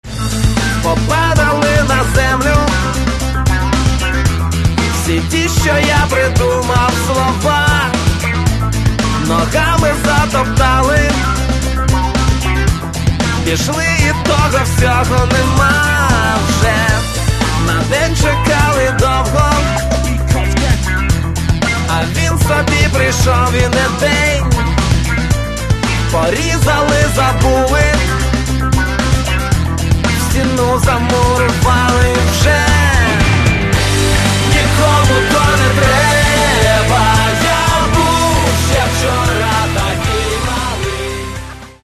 Каталог -> Хіп-хоп -> Збірки